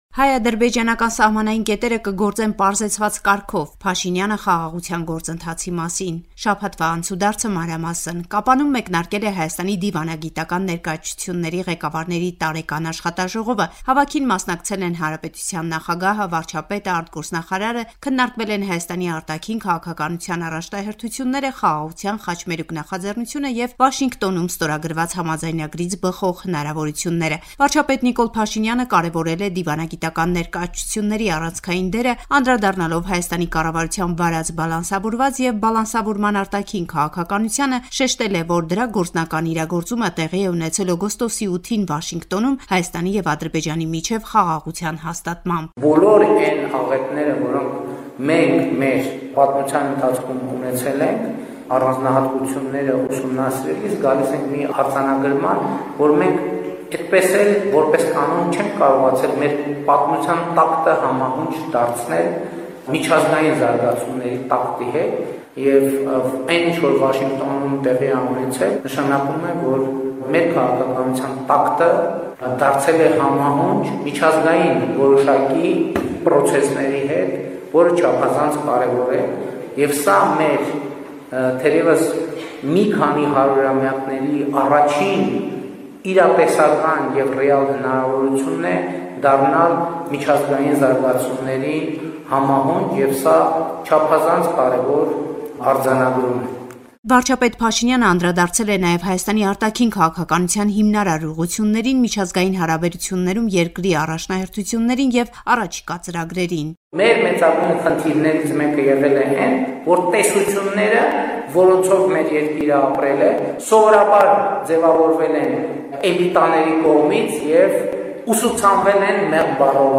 Latest news from Armenia, Artsakh and the Diaspora from our reporter